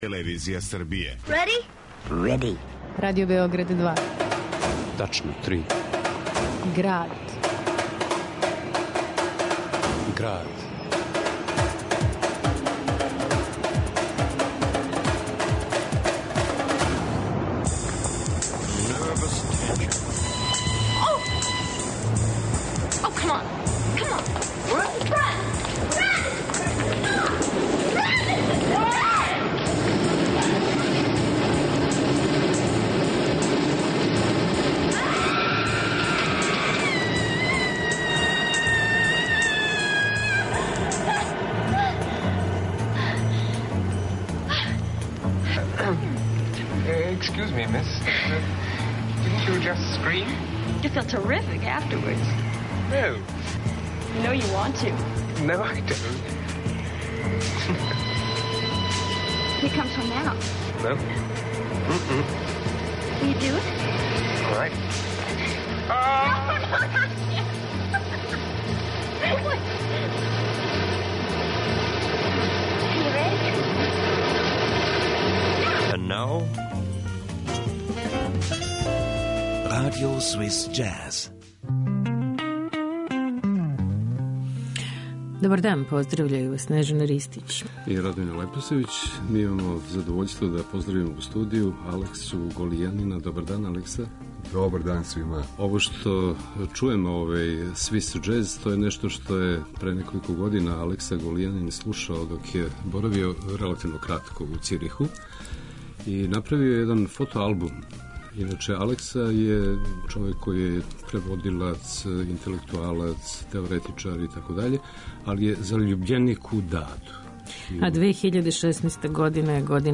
uz obilje arhivskih snimaka